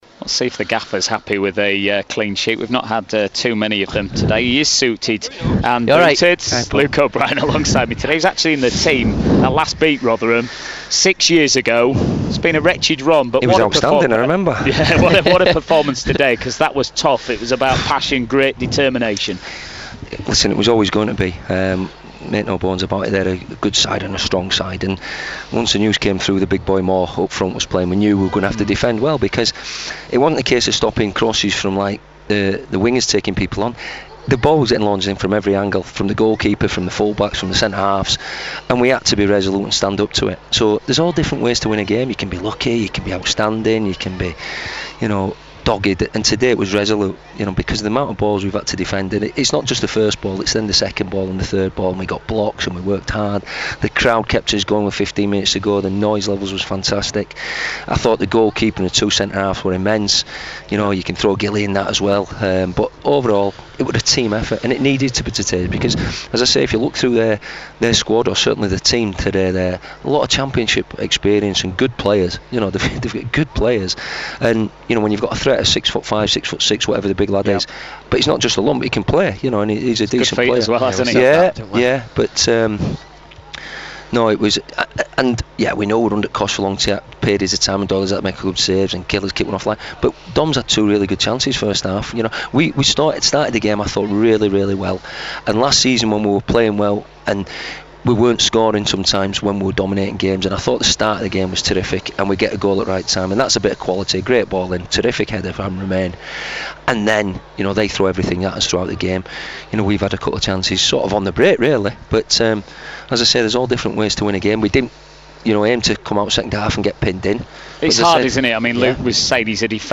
Stuart McCall interview post Rotherham